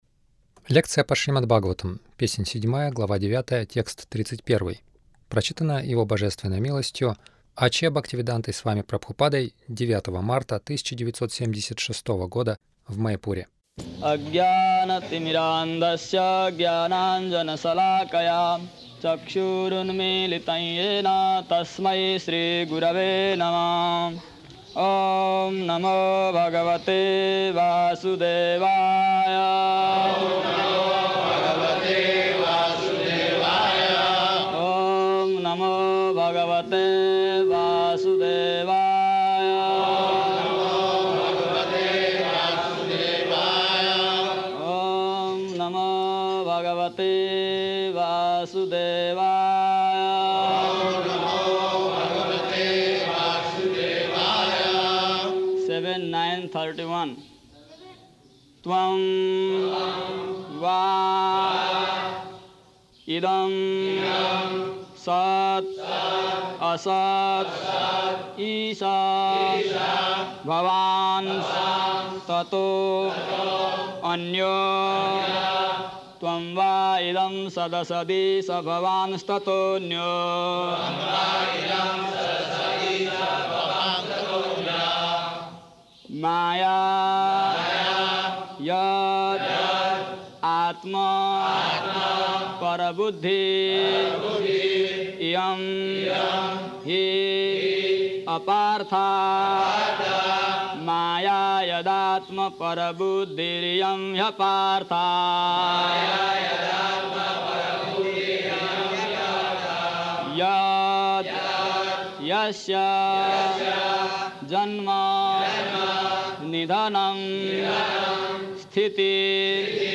Милость Прабхупады Аудиолекции и книги 09.03.1976 Шримад Бхагаватам | Маяпур ШБ 07.09.31 — Нет ничего кроме Кришны Загрузка...